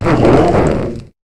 Cri de Grotichon dans Pokémon HOME.